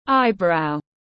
Eyebrow /ˈaɪ.braʊ/